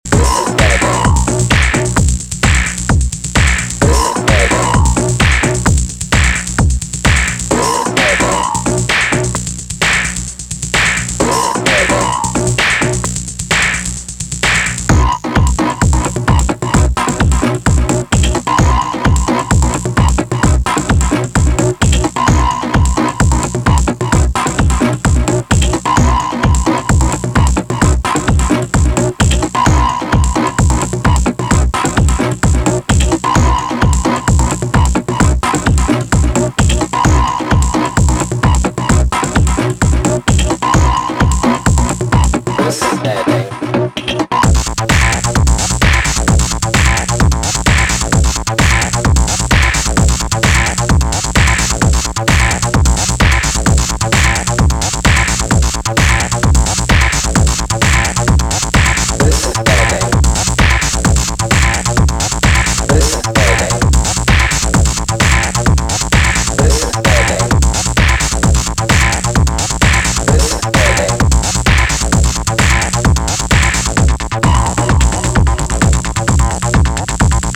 ビキビキと放電するかの如き激しい刺激のシーケンスを特徴とする4トラックス。
エグいEBM味がたまらないヘヴィアシッドチューン